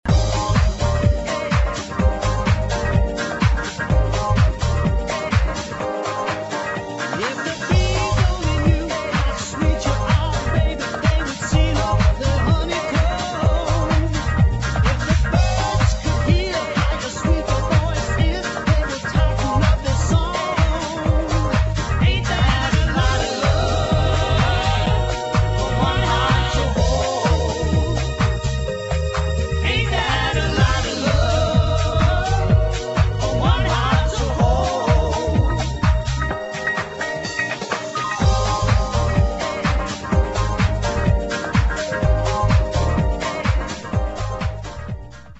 [ POP ROCK / HOUSE ]